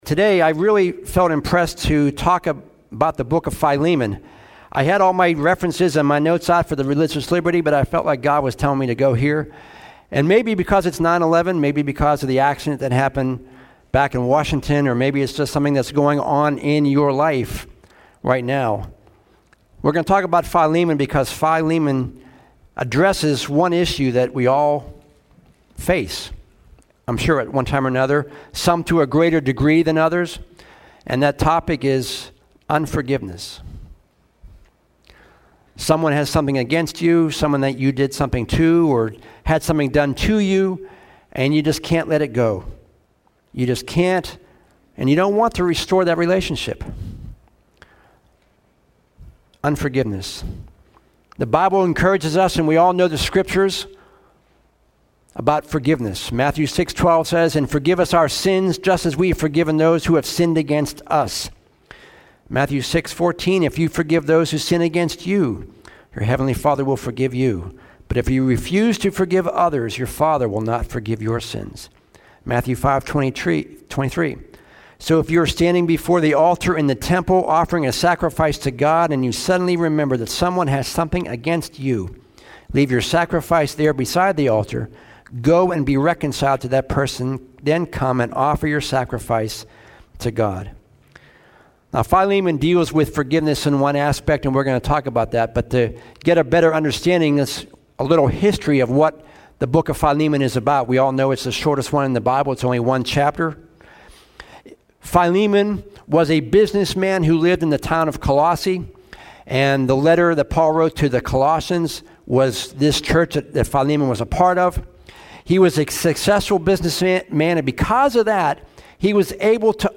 The Bible encourages us to forgive those who hurt us. Today's sermon took a look at the book of Philemon, as Onesimus, a former slave of Philemon's, sought forgiveness from Philemon for stealing from him and escaping.